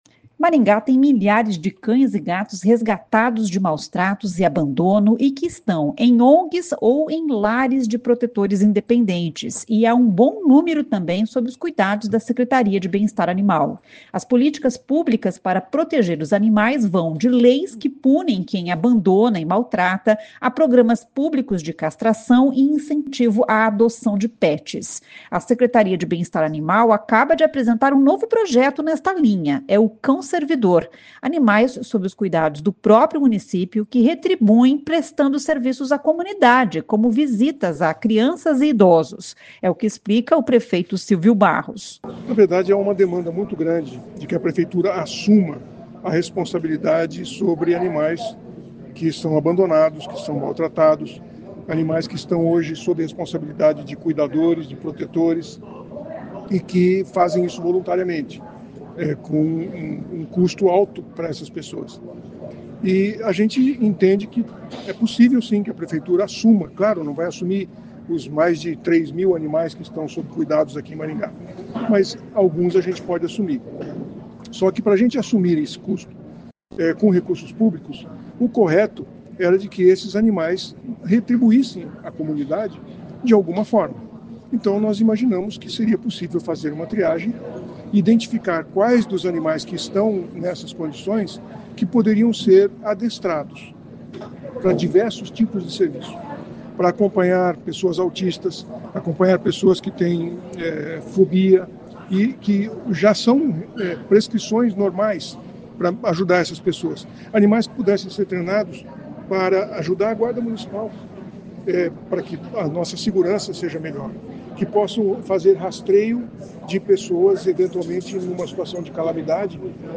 É o que explica o prefeito Sílvio Barros.